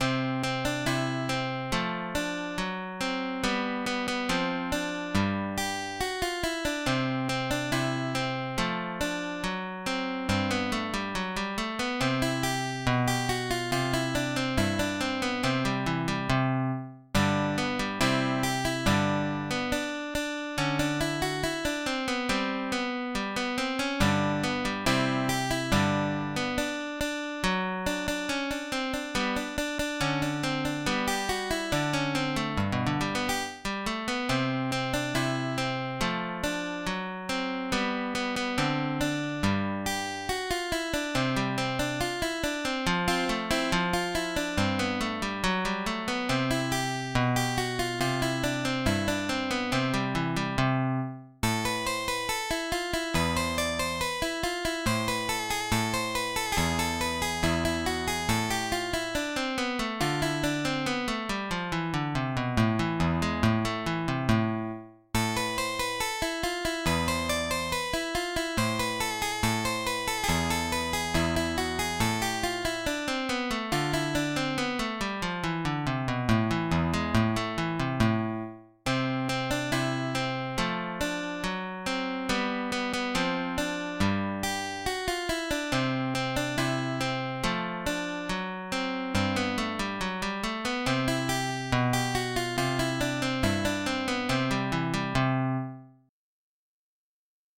in Do/Lam ***